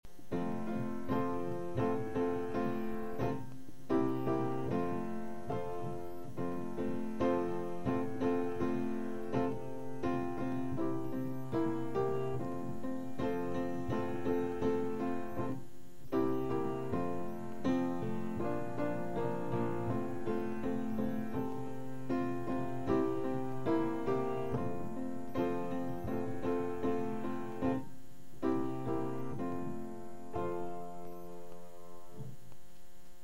その日の演奏を再現→
振り返ると私はアレンジとかこる方なんで、手引きにあった楽譜のコードに満足できず、事務センターにコードは楽譜の通りじゃなくても大丈夫なのか事前に確認し、かなり独自のアレンジに変えたピアノ伴奏を弾きました。